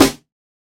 Snare 016.wav